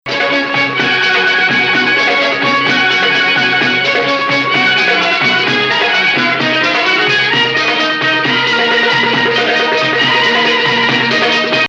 Bayati 1
going up to 5